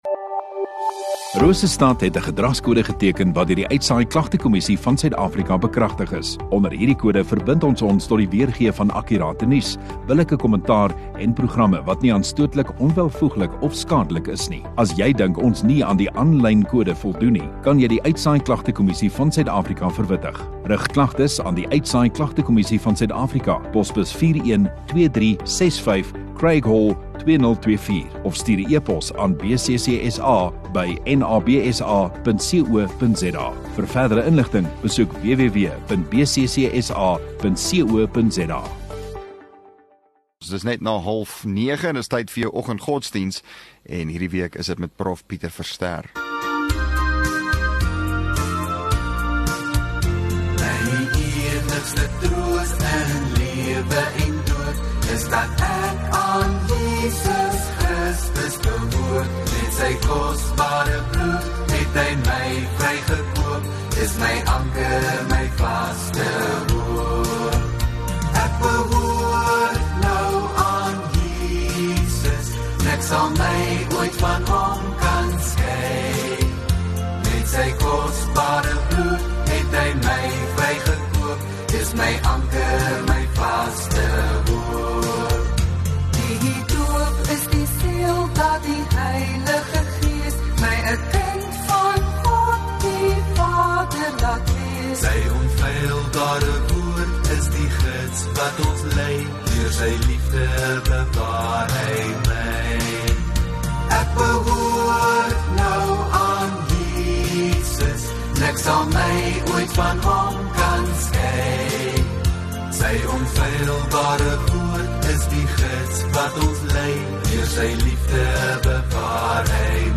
27 Mar Donderdag Oggenddiens